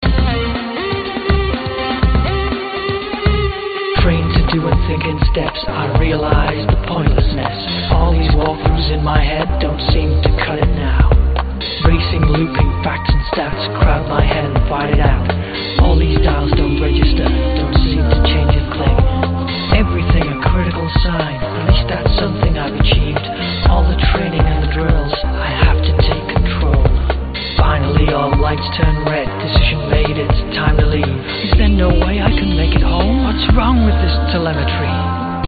环境 鼓声 电子 女声 电子音乐
声道立体声